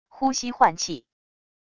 呼吸换气wav音频